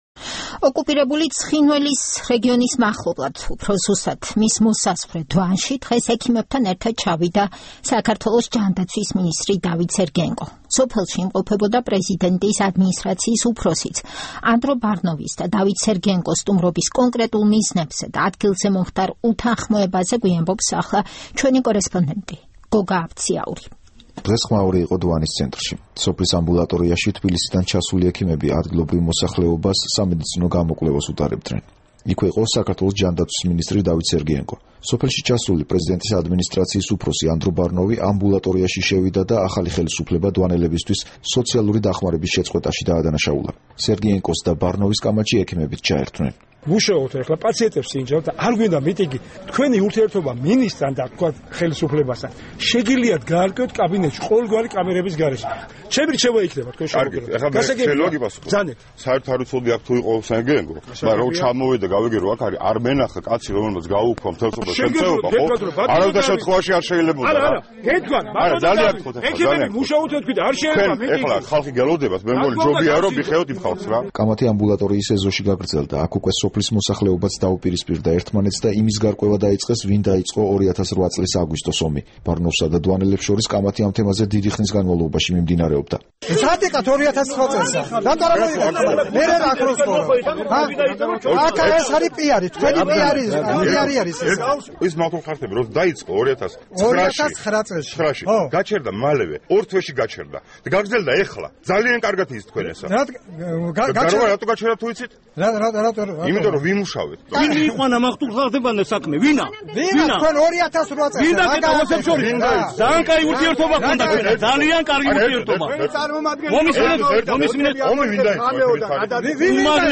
ანდრო ბარნოვისა და დავით სერგეენკოს კამათი დვანში